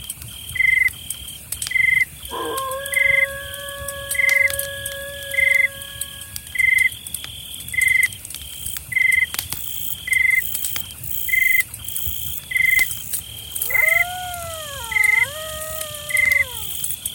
Bruits d’ambiance autour d’un feu de camp dans la crique du loup.